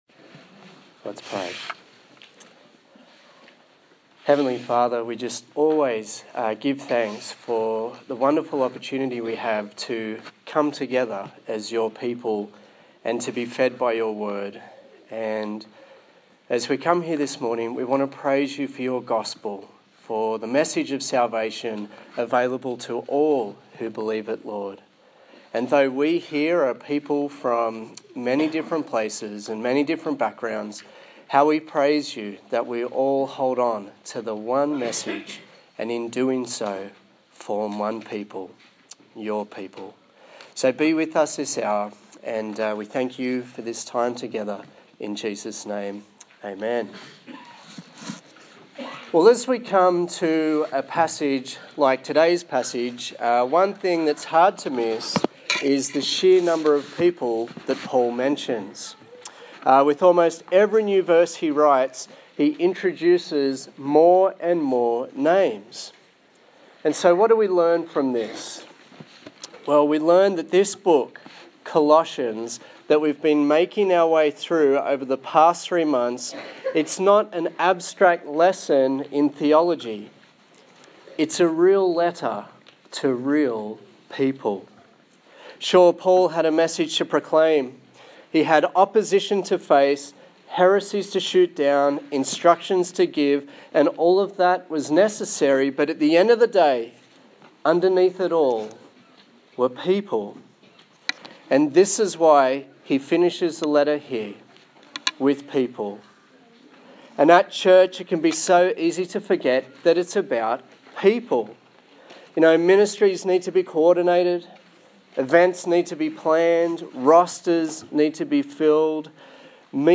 Colossians Passage: Colossians 4:7-18 Service Type: Sunday Morning